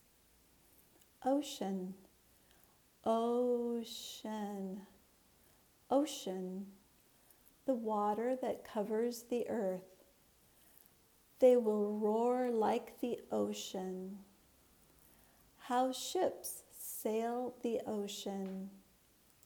/ˈəʊ ʃn/   (noun)